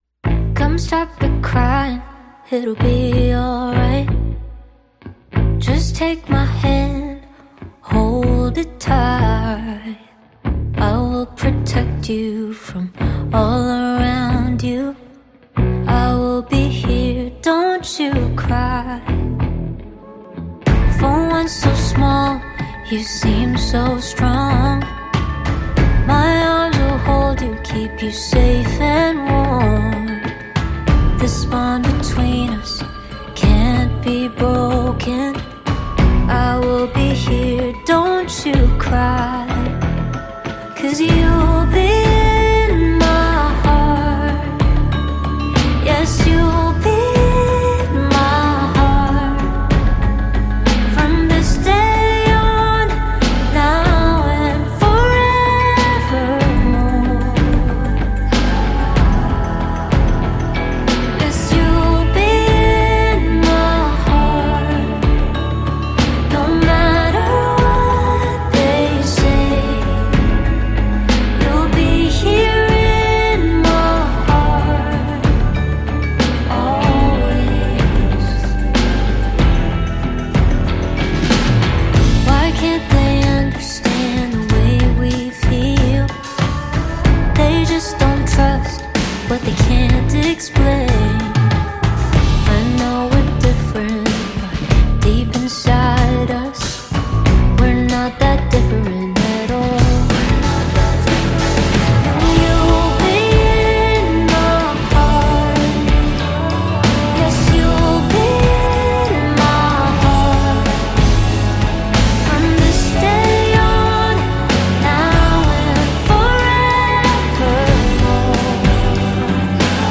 Label Pop